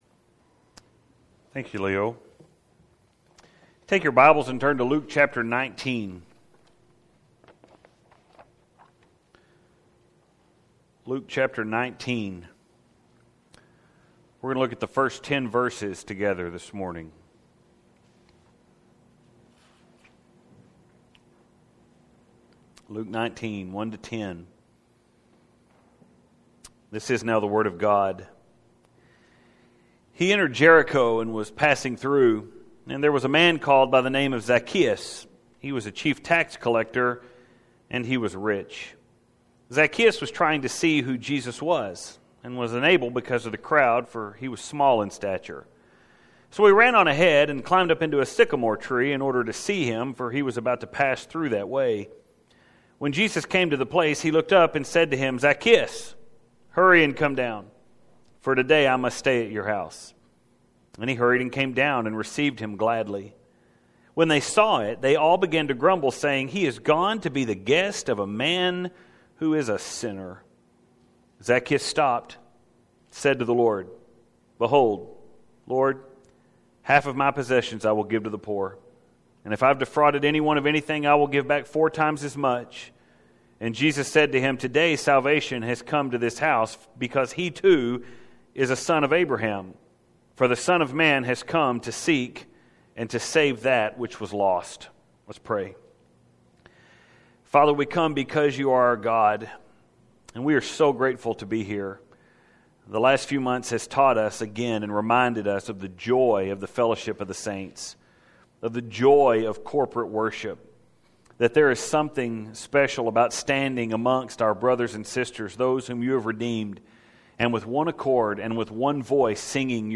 • If you’ve got that children’s song stuck in your head • And you think this sermon is all about how Zaccheus climbed that tree • Because he was seeking salvation then you’ve got it all wrong.